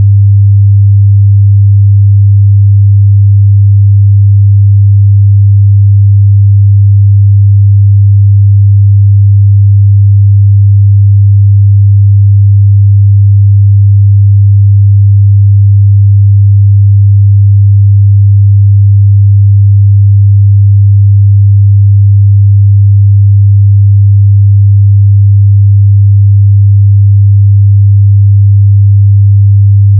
100hz Test Tone to use when optimizing gains (watch at 4:16 in the video above). This is a lower “bass” tone so you may not hear it on small speakers.